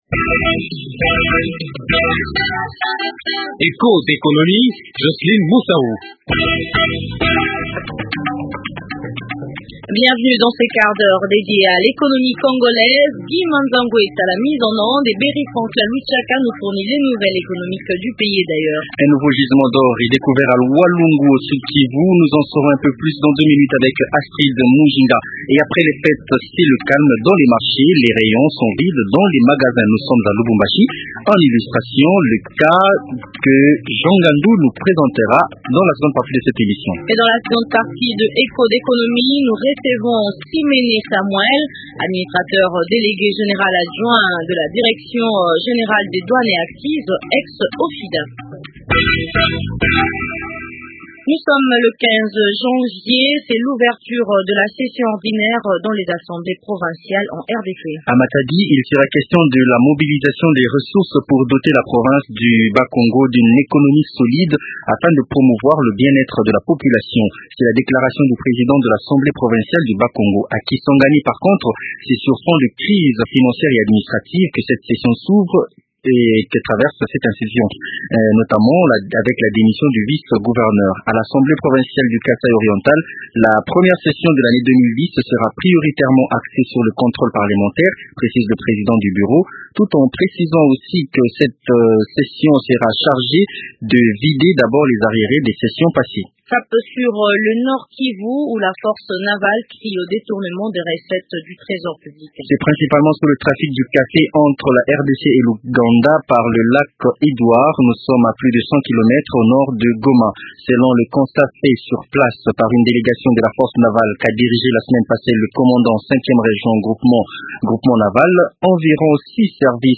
Les opérateurs économiques de Lubumbashi affirment que leurs affaires ont du mal à bien tourner. C’est l’éco reportage de la fin de cette semaine.